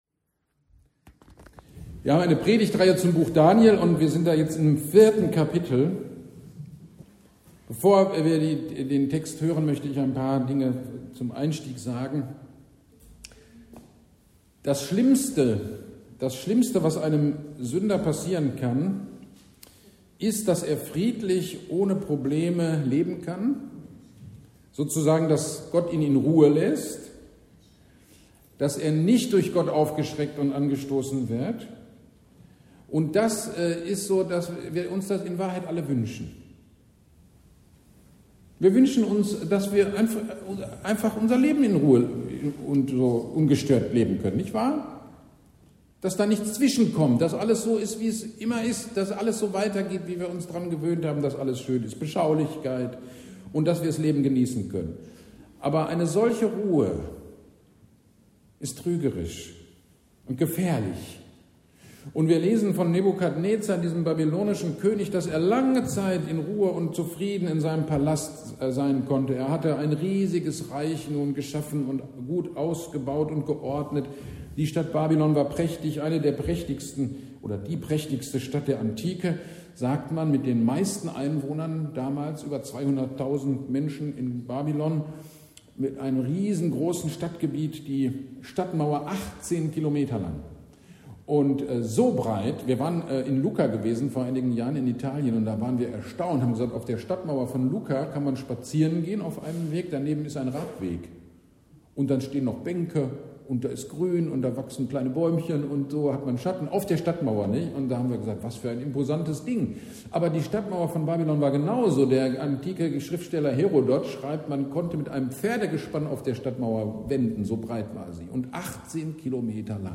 GD am 15.09.24 Predigt zu Daniel 4